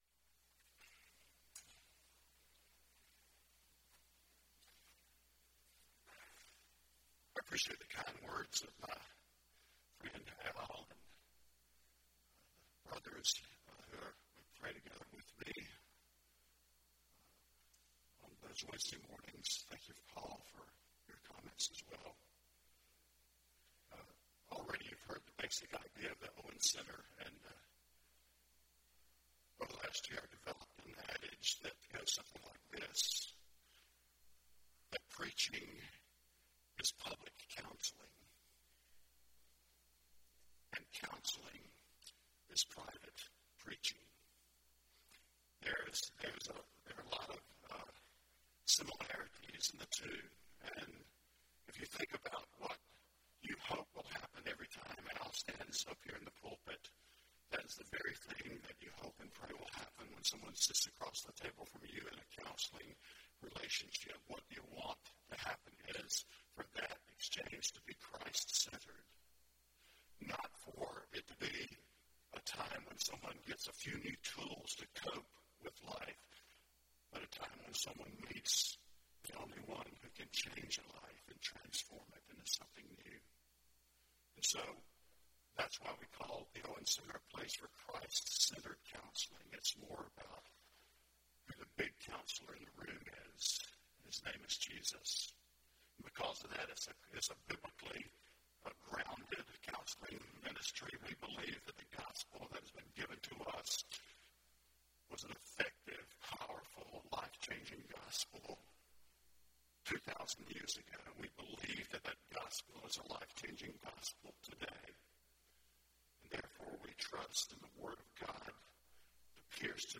Joint Worship of Auburn Churches
Sermon